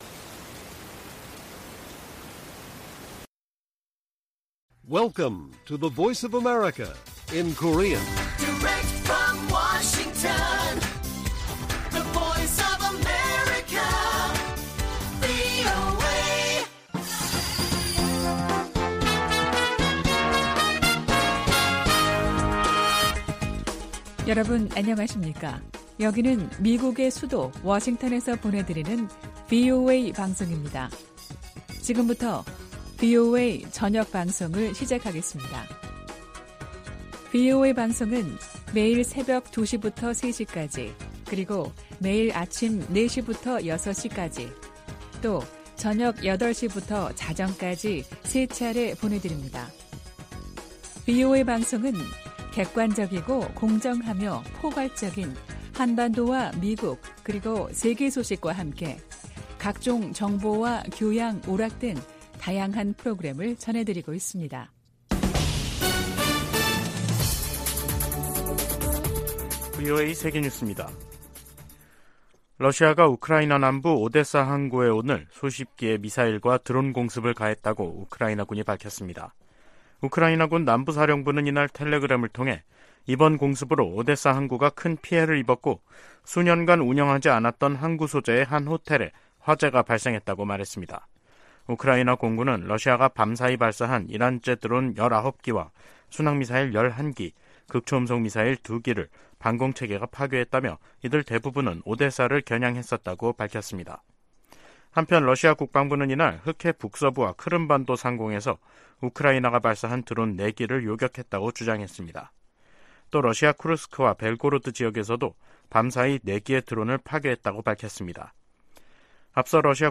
VOA 한국어 간판 뉴스 프로그램 '뉴스 투데이', 2023년 9월 25일 1부 방송입니다. 조 바이든 미국 대통령은 러시아가 우크라이나의 평화를 가로막고 있다면서 이란과 북한으로부터 더 많은 무기를 얻으려 하고 있다고 비판했습니다. 시진핑 중국 국가주석이 한국 방문 의사를 밝히고 관계 개선 의지를 보였습니다. 미국, 일본, 인도, 호주 4개국이 유엔 회원국에 북한과 무기 거래를 하지 말 것을 촉구했습니다.